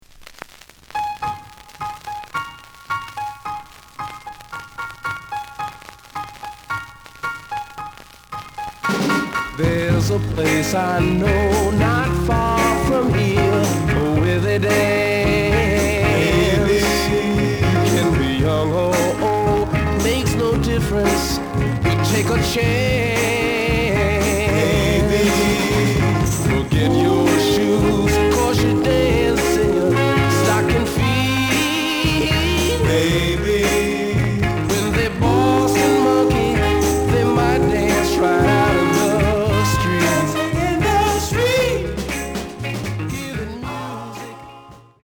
The listen sample is recorded from the actual item.
●Genre: Soul, 60's Soul